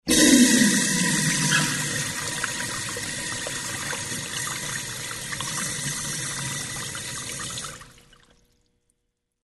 Звуки мочеиспускания
Смыли писсуар